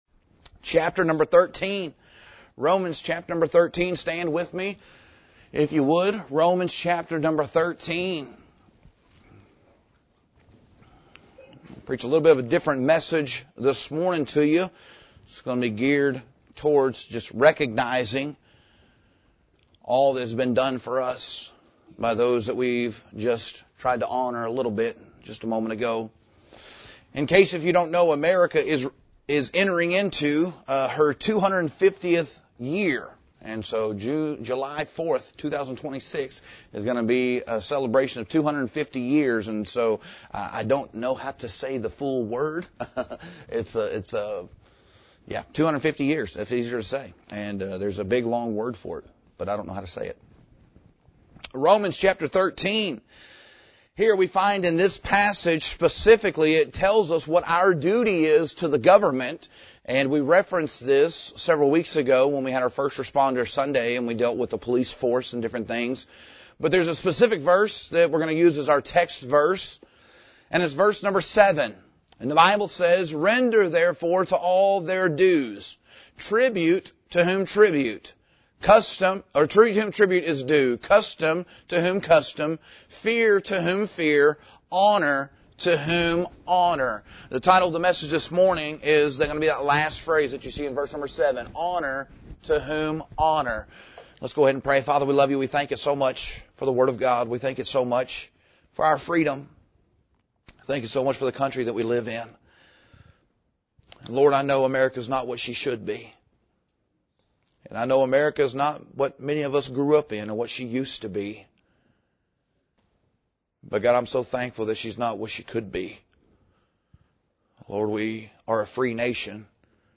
The title of this sermon is “Honor to Whom Honor.”